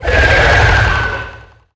Sfx_creature_shadowleviathan_chitter_03.ogg